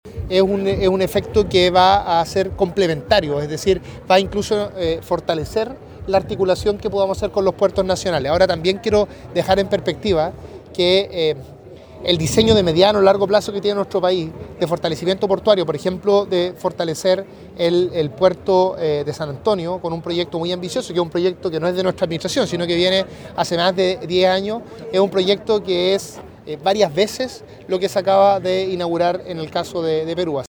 Consultado el ministro de economía, Nicolás Grau, dijo que Chancay, más que una amenaza, representa una oportunidad para el desarrollo portuario en Chile: “Es un efecto complementario, va incluso a fortalecer la articulación que podamos hacer con los puertos nacionales”.